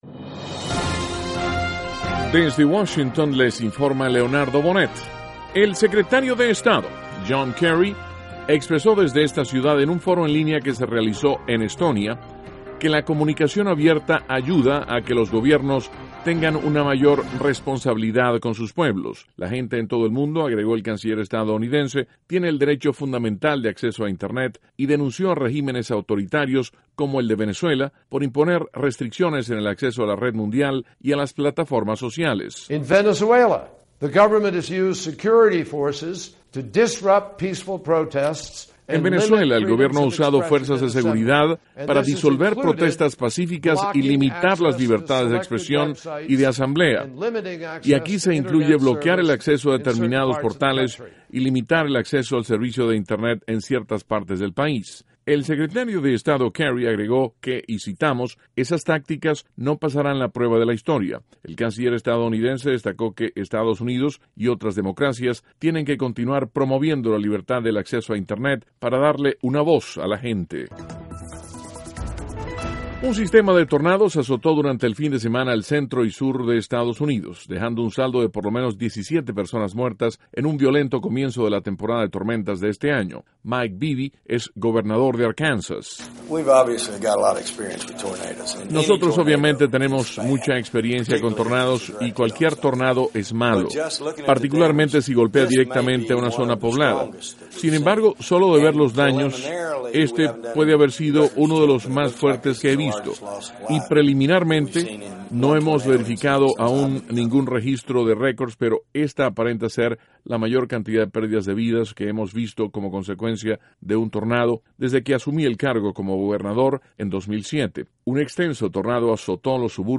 (Sonido – Kerry) 2.-Tornados causan desastres en partes de Estados Unidos. (Sonido – Mike Been, gobernador de Arkansas) 3.-Presidente de la Comisión de Libertad de Prensa denunció la censura “institucionalizada” en Venezuela.